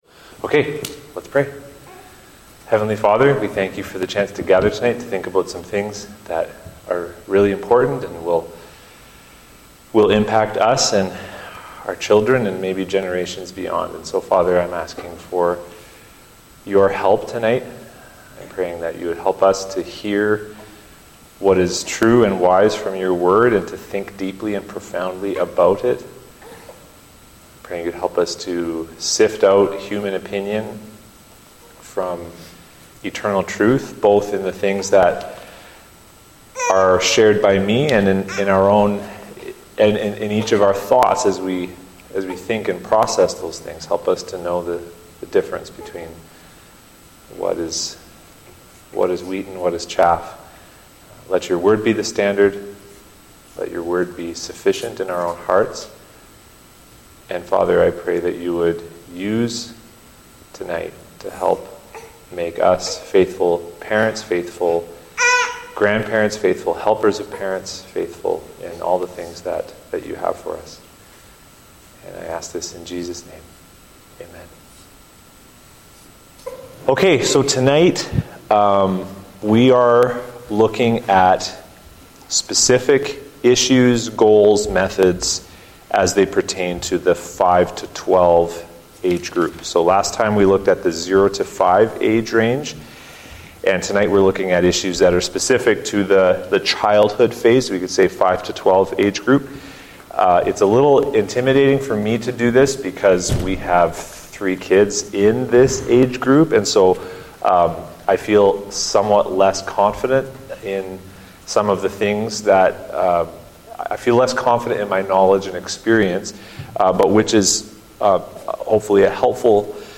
Parenting Workshop #5